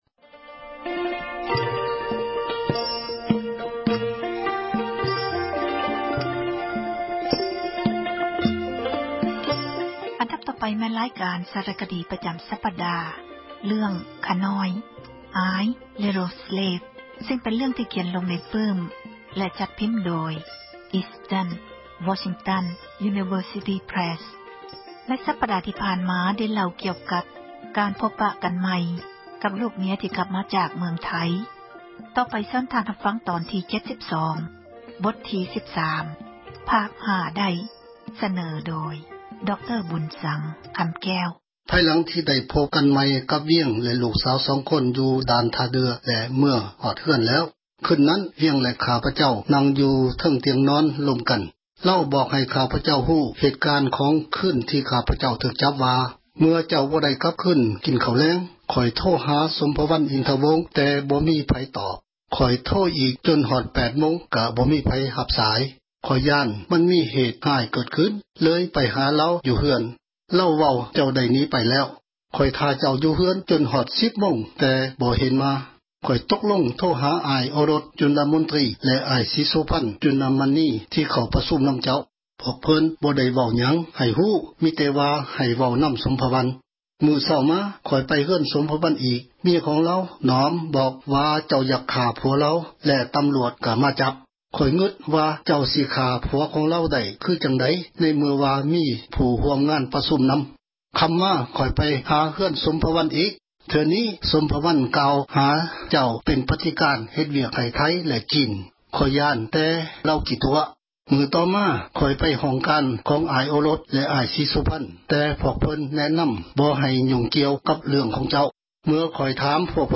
ສາຣະຄະດີ ເຣື່ອງ ”ຂ້ານ້ອຍ" (I Little Slave) ພາຄ 72 ສເນີໂດຍ